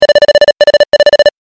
文字送りa長.mp3